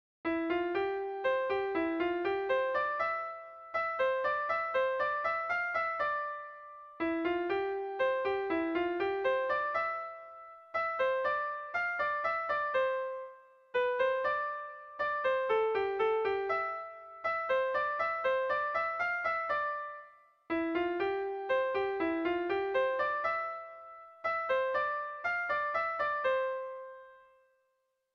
Erlijiozkoa
AABA